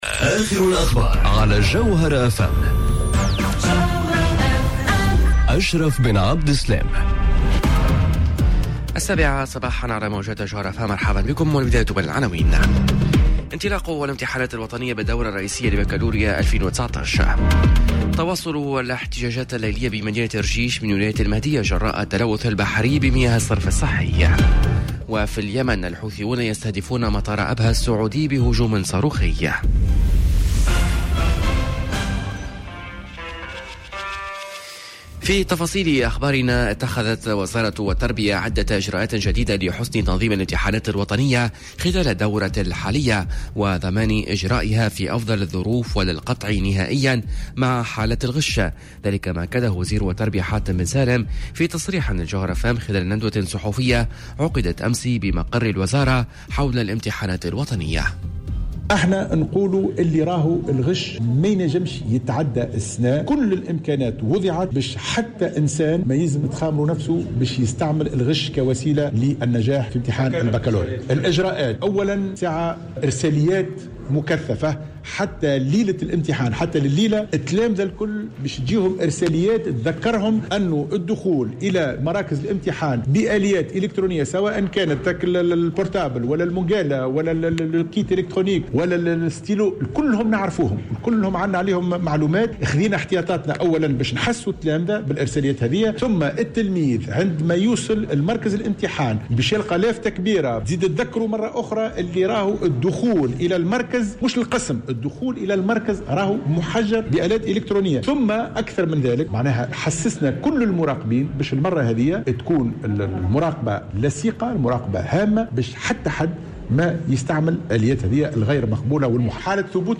نشرة أخبار السابعة صباحا ليوم الإربعاء 12 جوان 2019